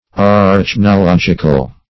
Arachnological \A*rach`no*log"ic*al\, a. Of or pertaining to arachnology.